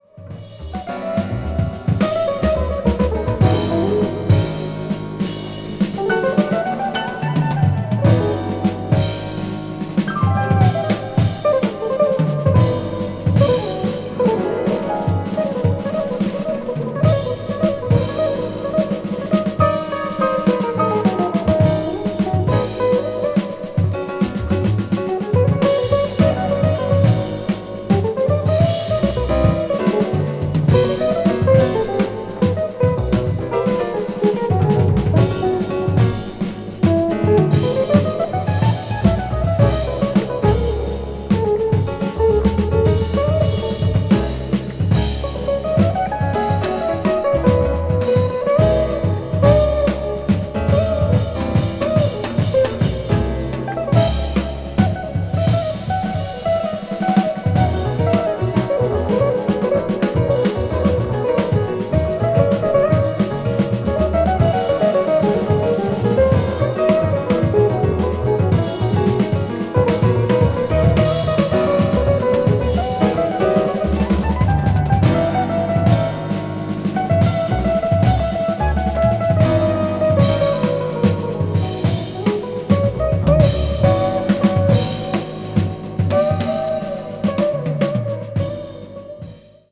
media : EX+/EX+(わずかにチリノイズが入る箇所あり)
contemporary jazz   deep jazz   experimental jazz